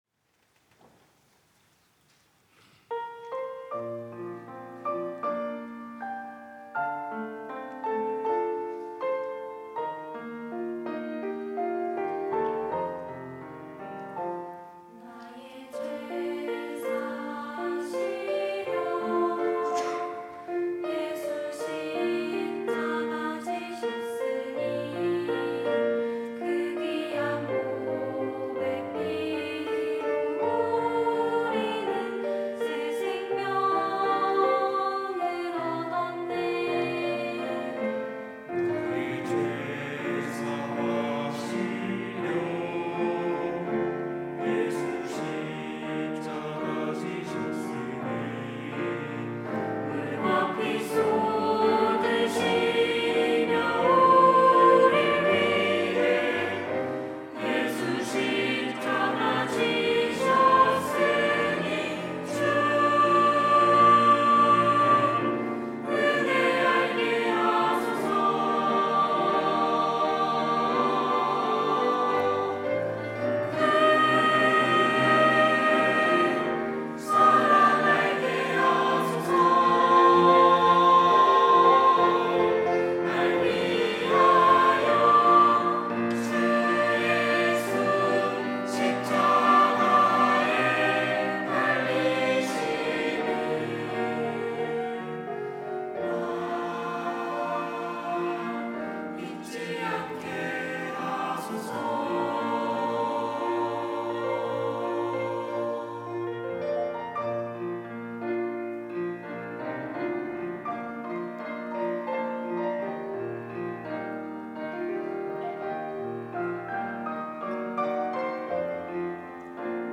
특송과 특주 - 은혜 알게 하소서
청년부 카이노스 찬양대